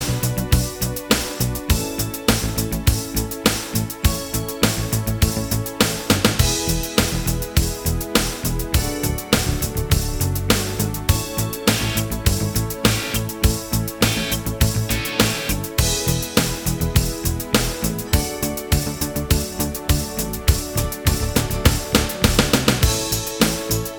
Minus Main Guitars Pop (1980s) 4:03 Buy £1.50